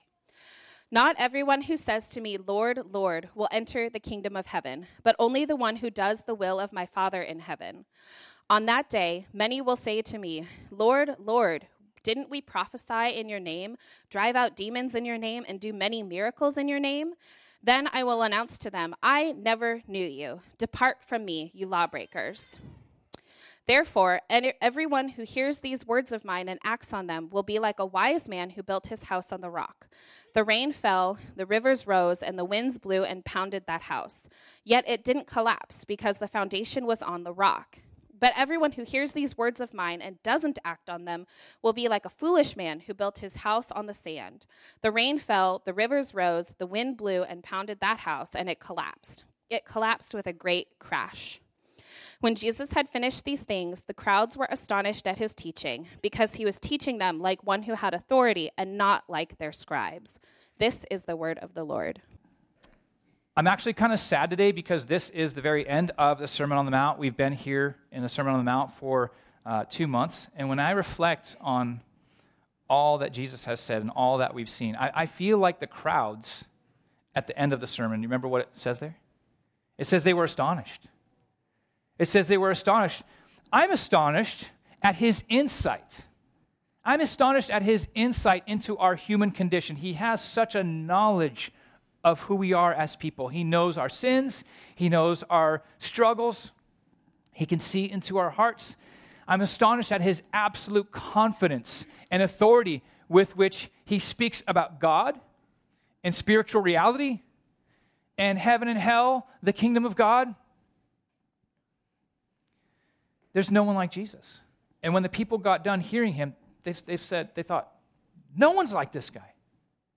This sermon was originally preached on Sunday, March 24, 2024.